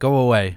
Voice Lines / Dismissive
go away.wav